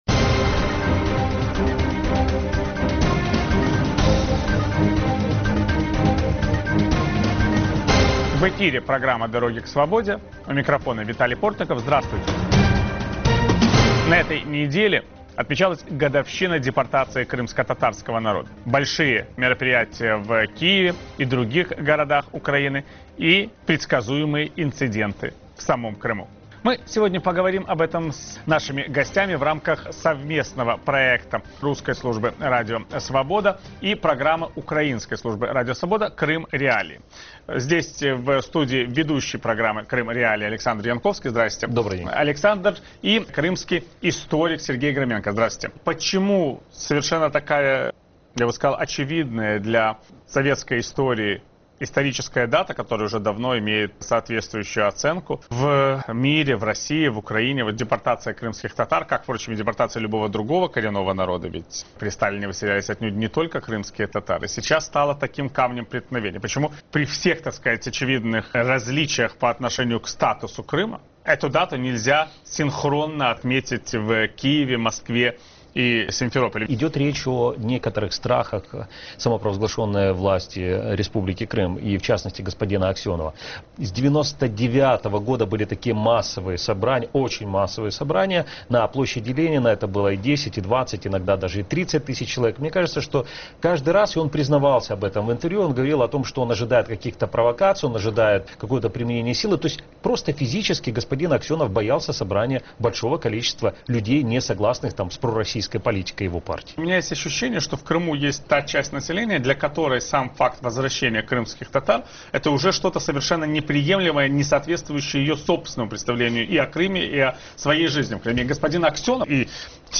Ведет эфир Виталий Портников.